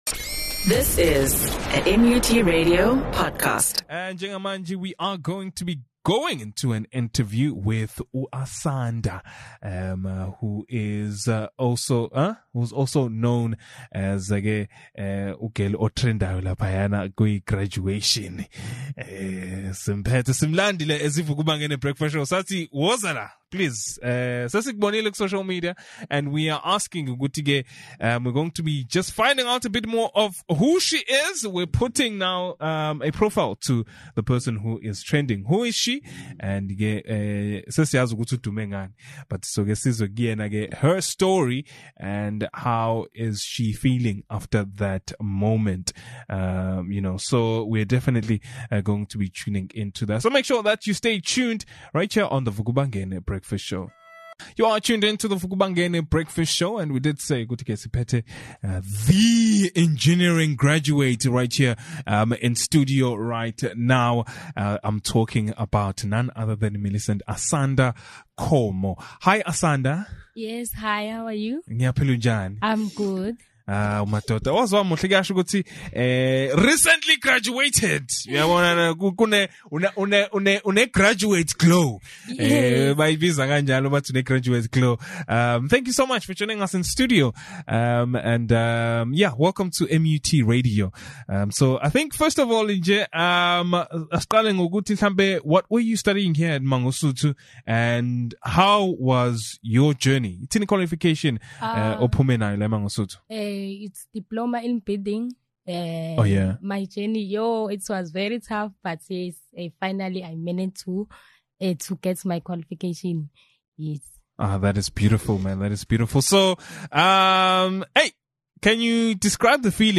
The VukuBangene Breakfast Show had an interview